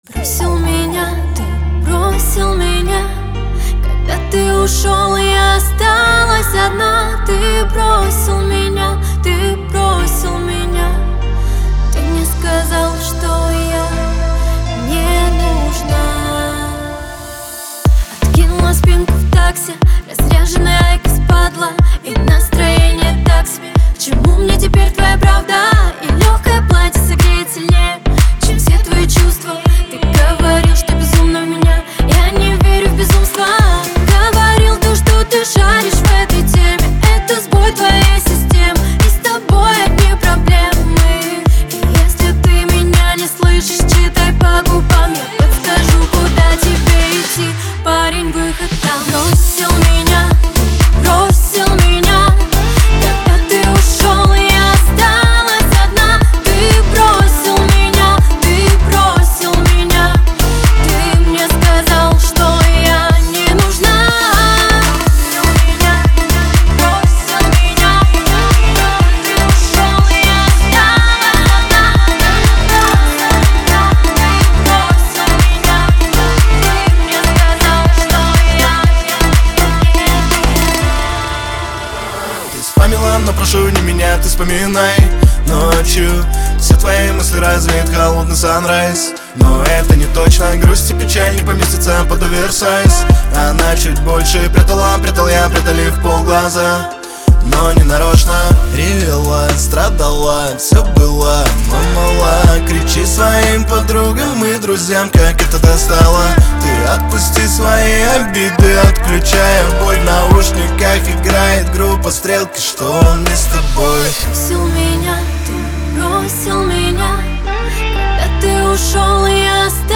дуэт
ХАУС-РЭП
грусть , pop
эстрада